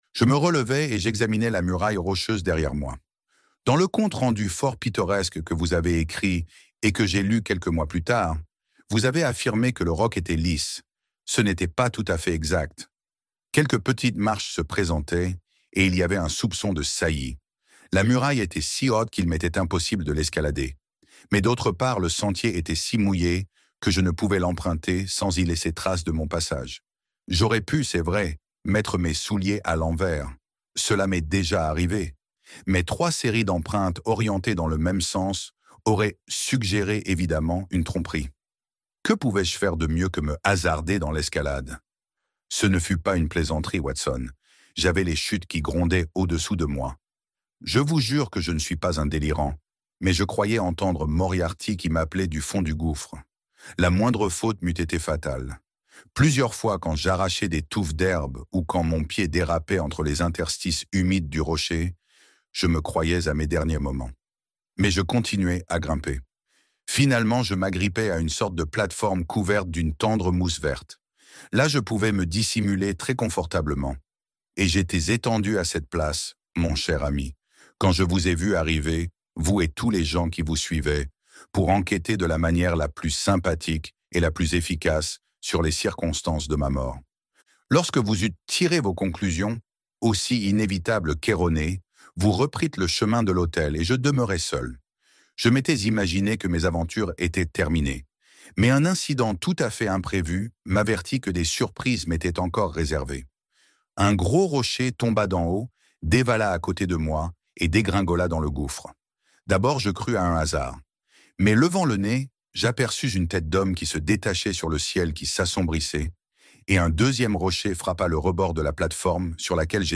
Le Retour de Sherlock Holmes - Livre Audio